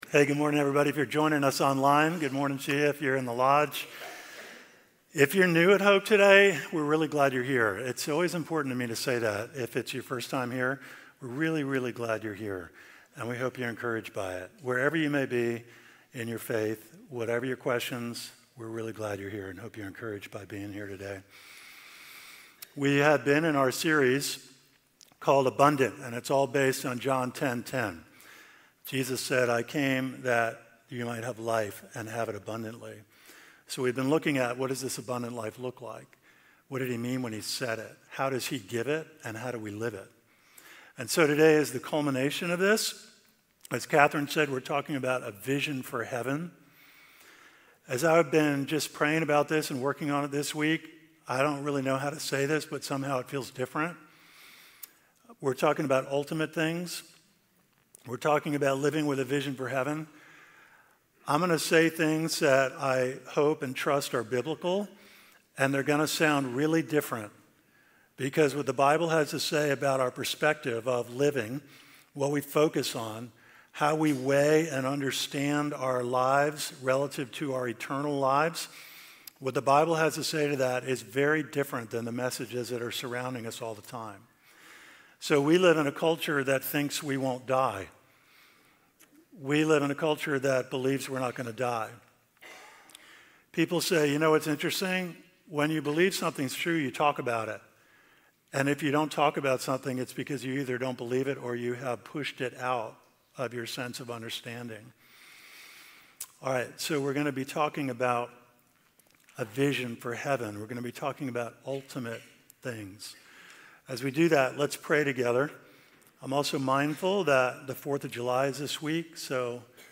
For the next seven weeks, we’ll explore these questions and learn what it means to have life in Christ in our new sermon series, Abundant.